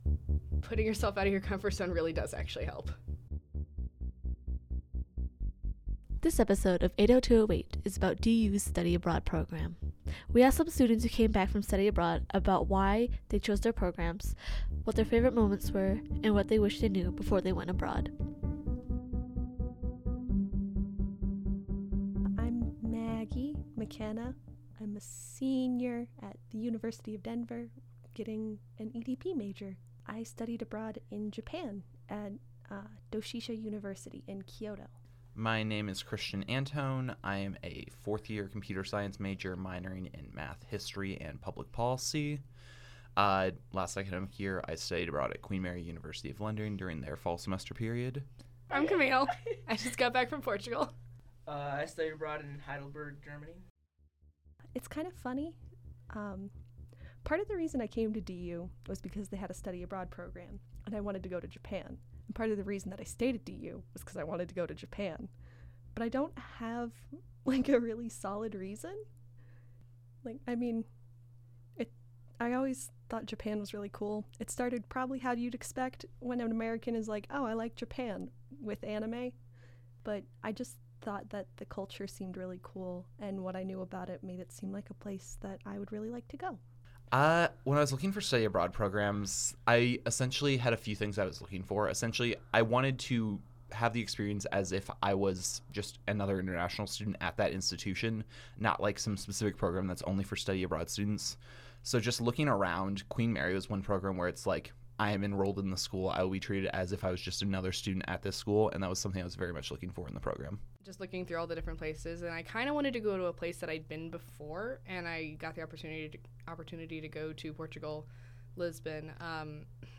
The four students interviewed gave plenty of examples from grocery shopping in a foreign country to being in a big city for the first time.
• Background music - "Night Owl" By Broke For Free